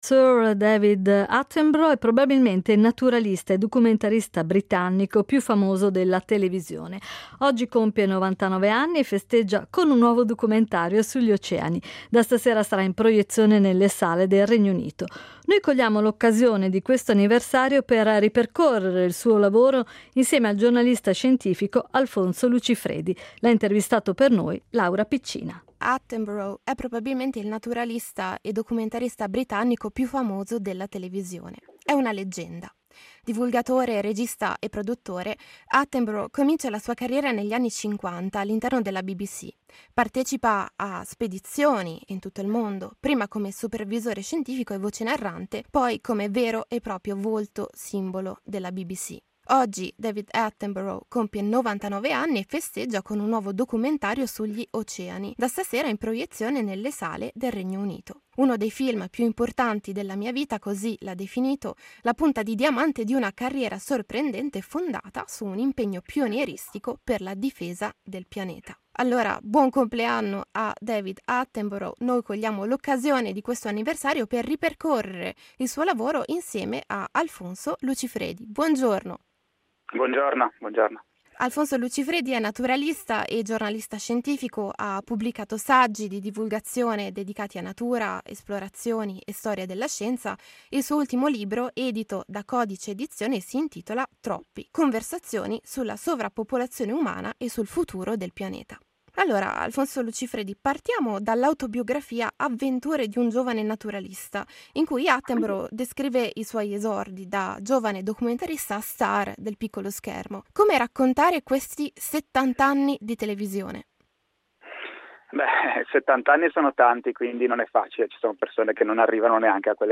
L’ha intervistato